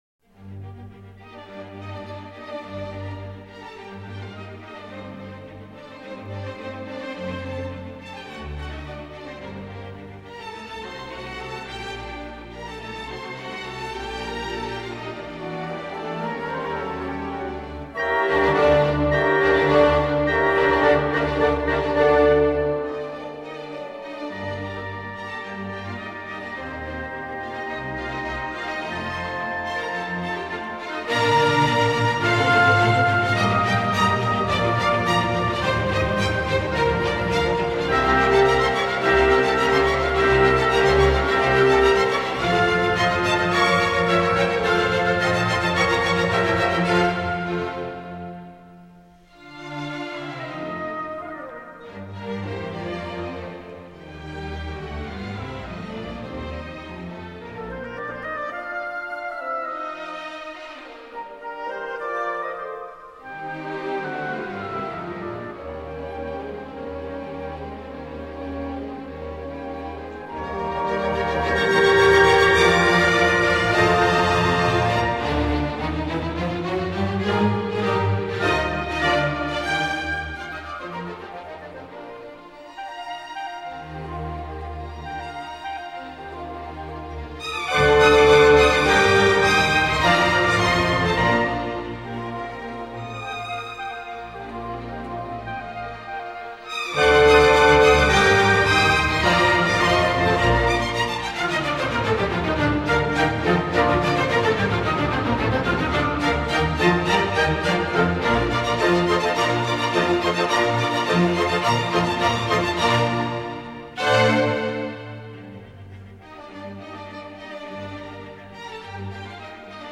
این اثر جلوه‌ای از جنبه‌های تاریک‌تر زندگی بشر را بازتاب میدهد. سمفونی شماره ۴۰ با ملودی‌های پیچیده اما ساده نما، تضاد میان اندوه و زیبایی را آشکار میکند.